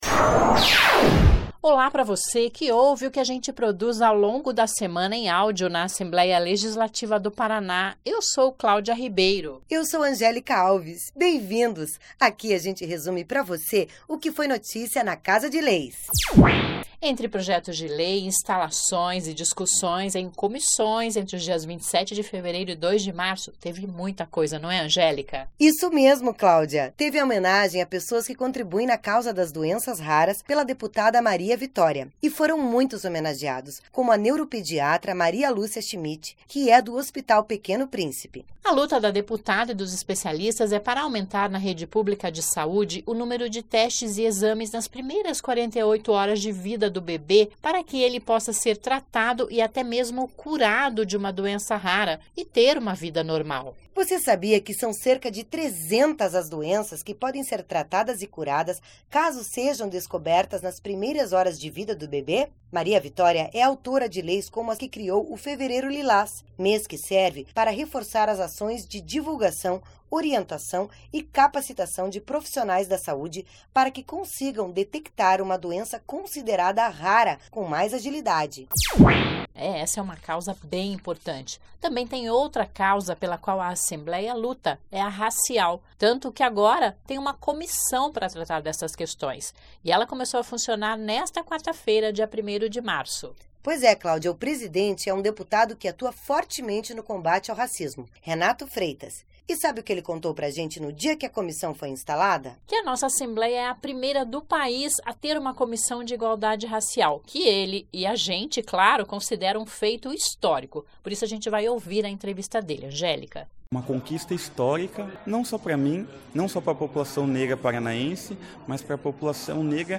Resumo da semana: